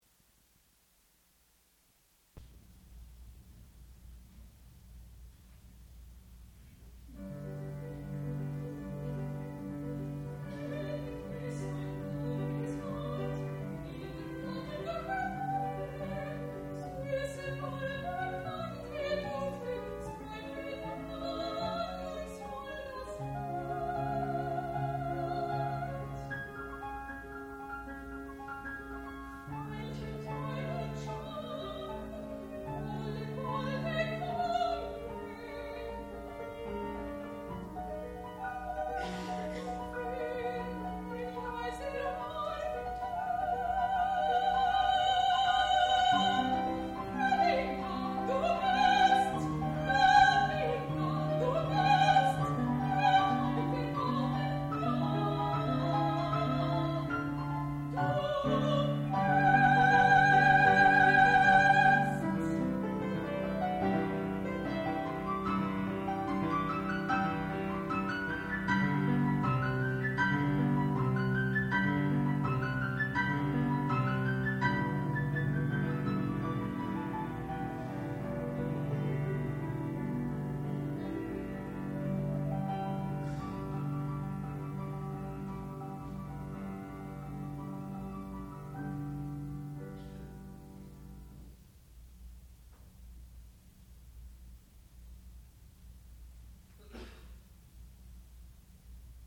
sound recording-musical
classical music
Graduate Recital
soprano